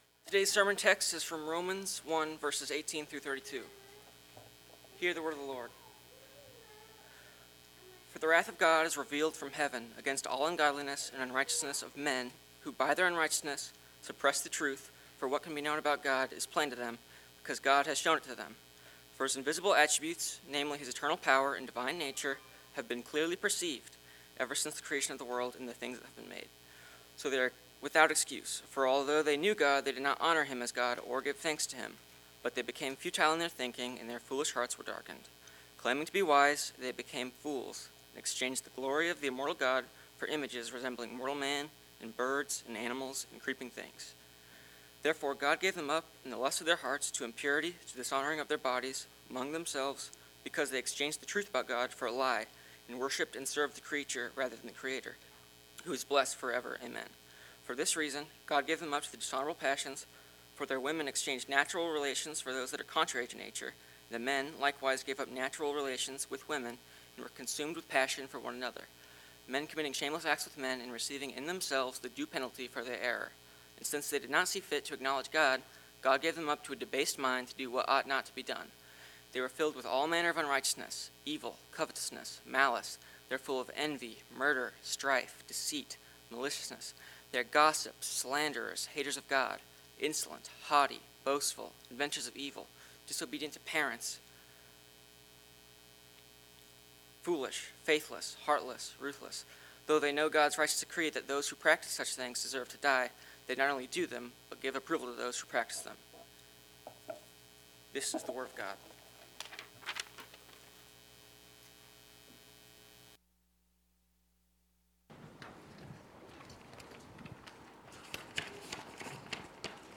Passage: Romans 1:18-32 Service Type: Sunday Morning